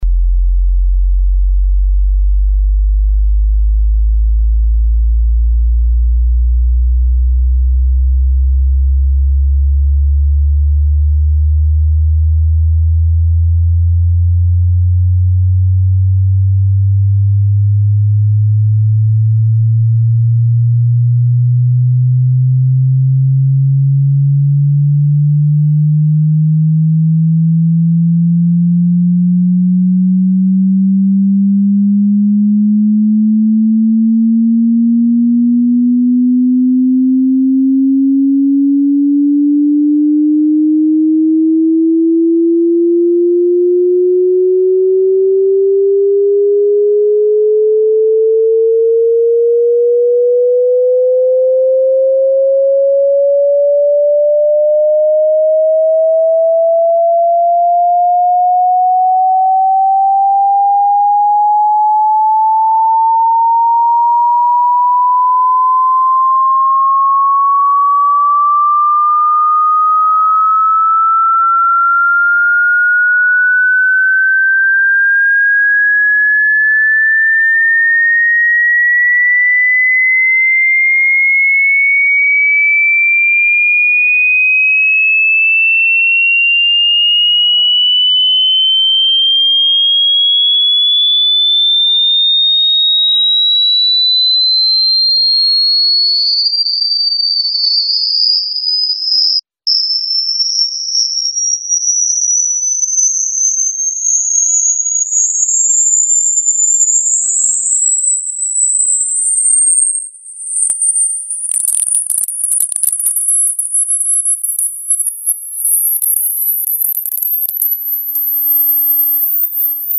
Звуки удаления воды
Подборка включает различные частоты и тональности, оптимальные для очистки наушников, смартфонов и ноутбуков.